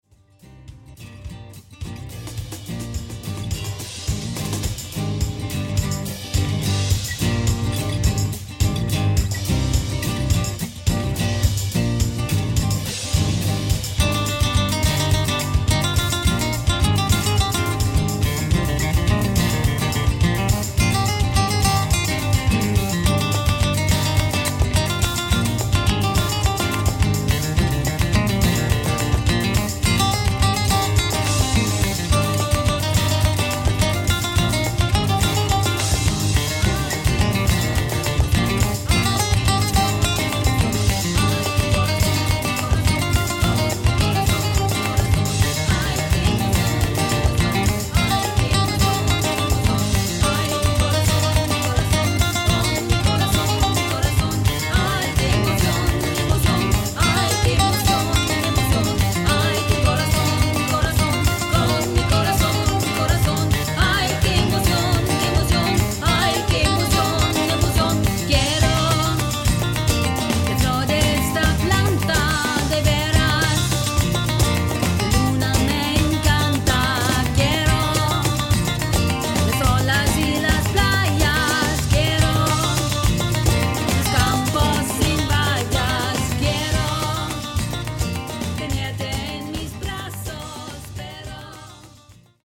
Akustik-Gitarre, Gesang, Palmas
Cajon, Gesang, Palmas
Flamenco-Gitarre, Cajon, Gesang
• Unplugged
• Latin/Salsa/Reggae